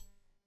Switch Click
A crisp, decisive mechanical switch click with tactile snap and brief resonance
switch-click.mp3